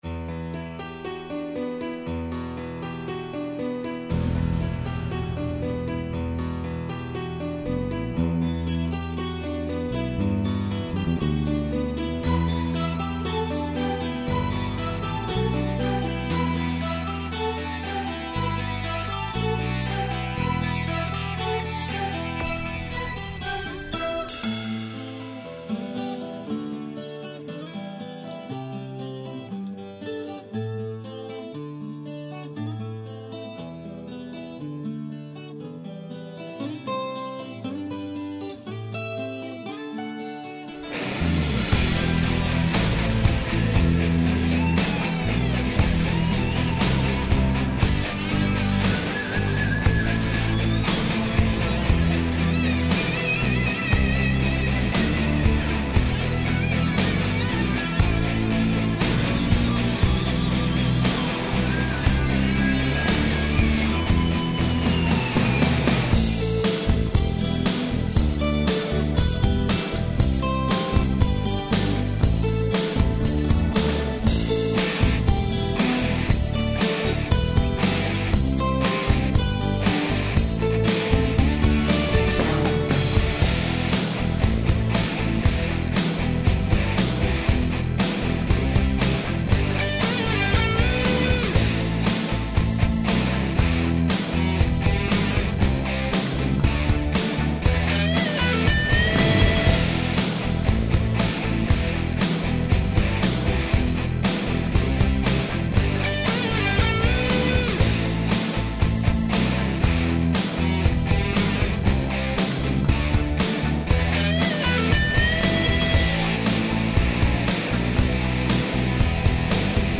минусовка версия 17235